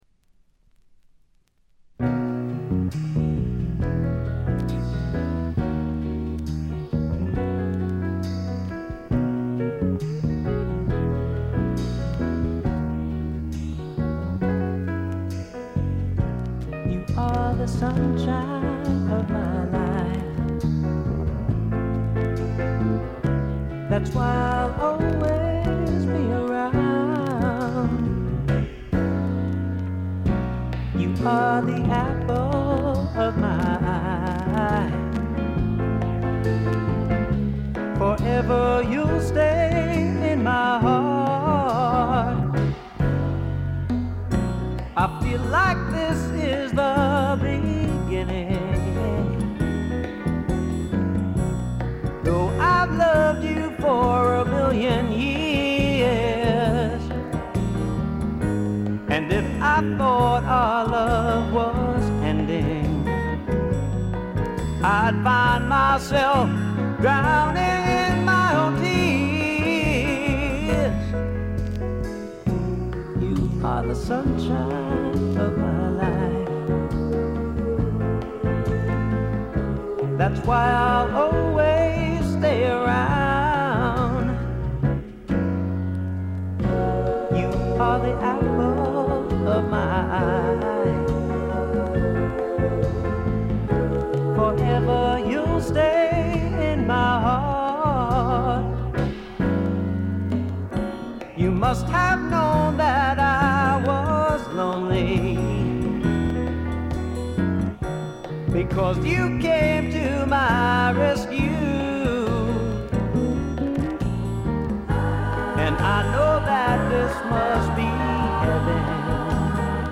軽微なチリプチ少々。
定番的に聴きつがれてきたプリAOR、ピアノ系シンガーソングライター作品の大名盤です。
試聴曲は現品からの取り込み音源です。
Keyboards, Percussion, Vocals